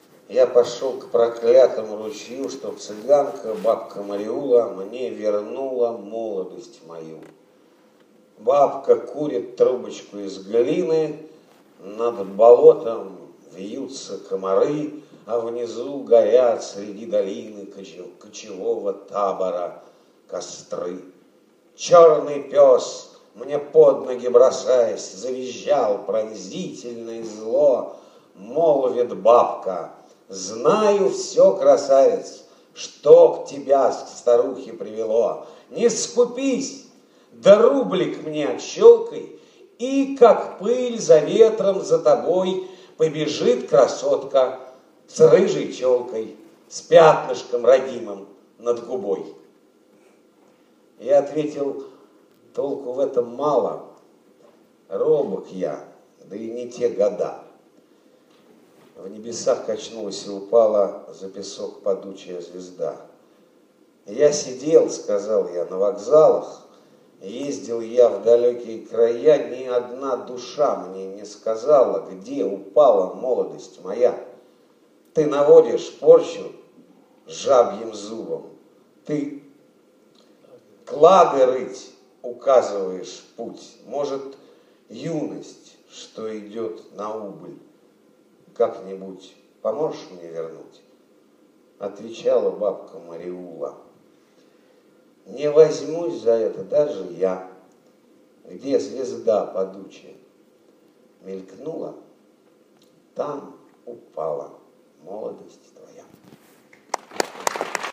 1. «Дмитрий Кедрин (читает Ефремов) – Бабка Мариула» /